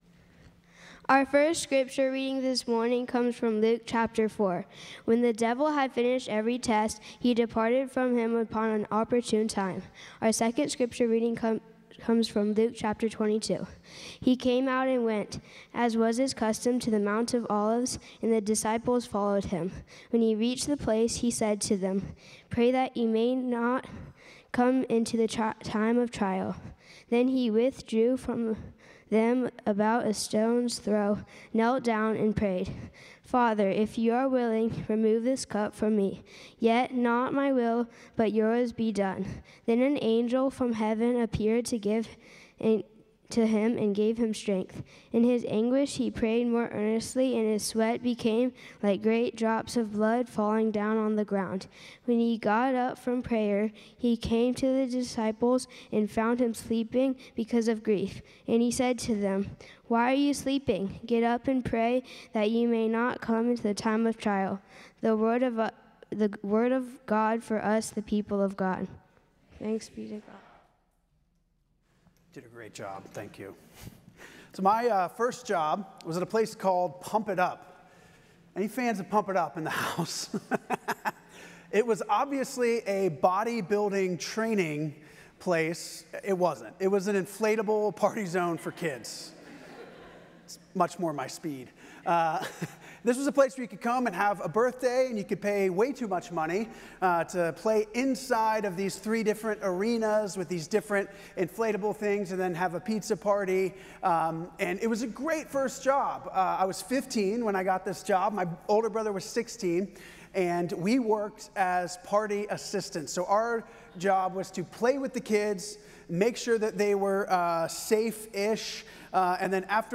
First Cary UMC